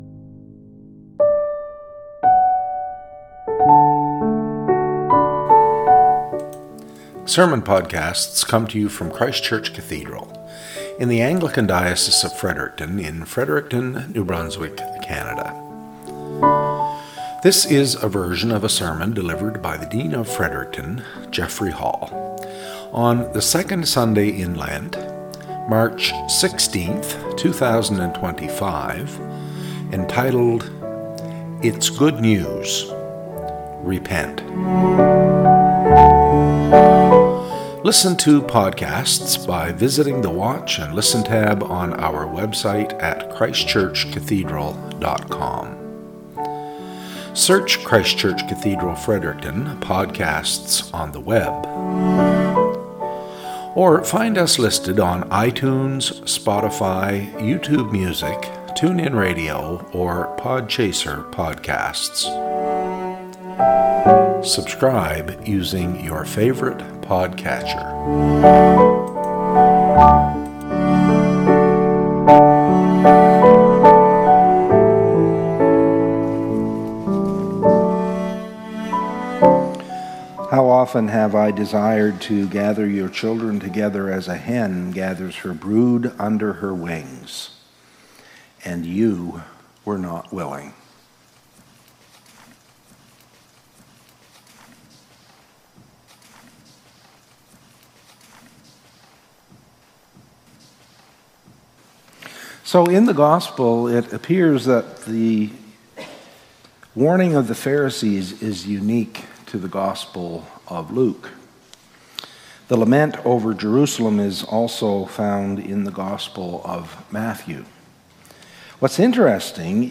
Cathedral Podcast - SERMON -
Podcast from Christ Church Cathedral Fredericton
Lections for the Second Sunday in Lent Year C